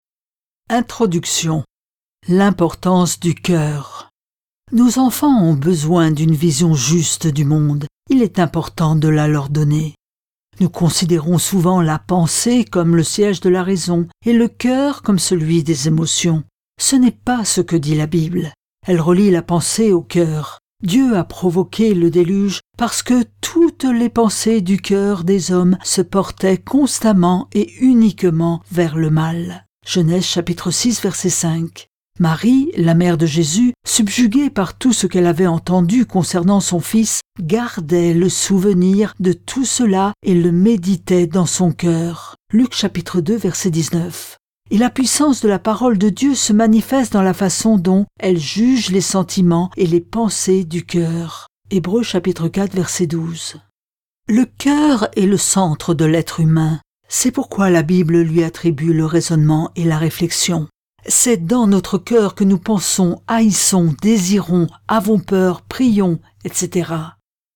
Click for an excerpt - Papa, maman, vous avez pas 5 minutes ? de Tedd Tripp, Margy Tripp